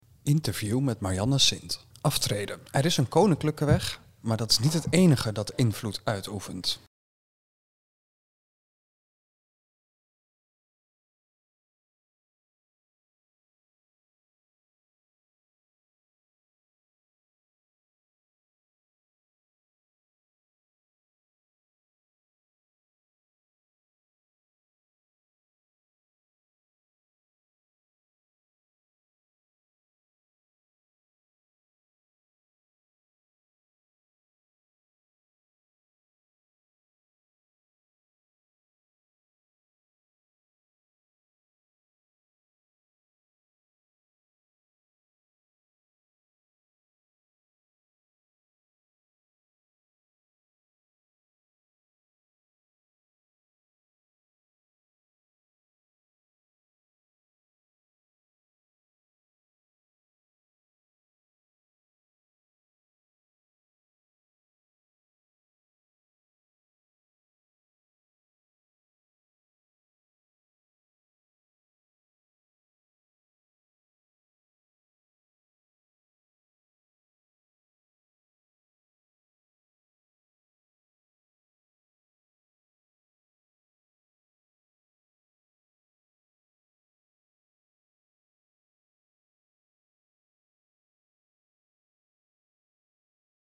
Interview met Marjanne Sint